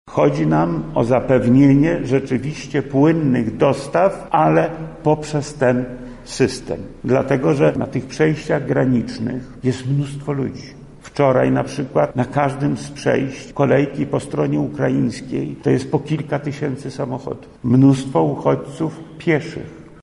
Apeluję do samorządów i organizacji pozarządowych, korzystajmy z tego systemu – mówi wojewoda lubelski Lech Sprawka: